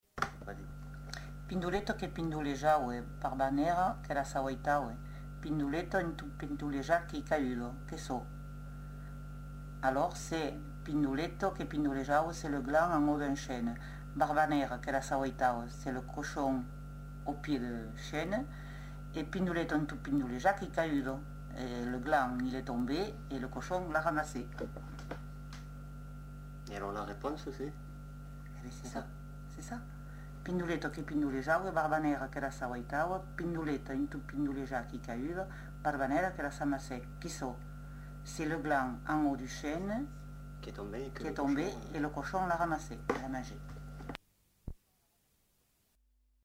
Devinette sur le gland et le cochon